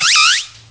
pokeemerald / sound / direct_sound_samples / cries / snivy.aif
-Replaced the Gen. 1 to 3 cries with BW2 rips.